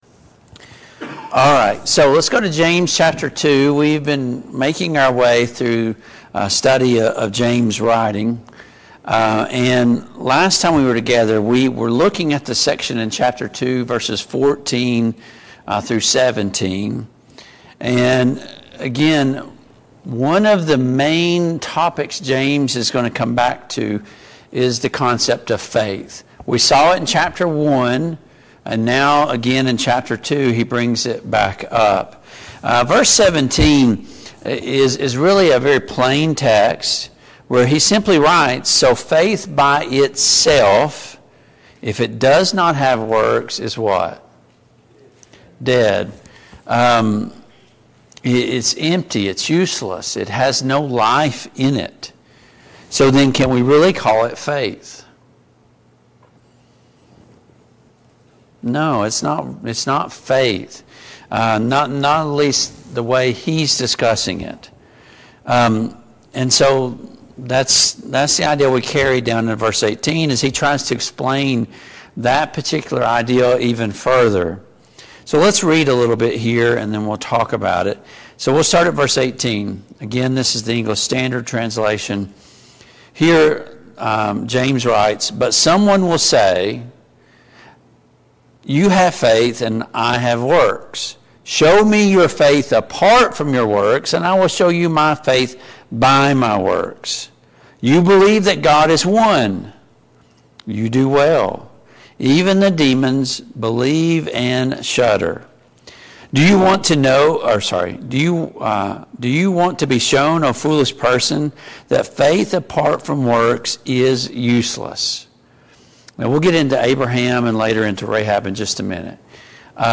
Service Type: Family Bible Hour Topics: Belief , Faith , Works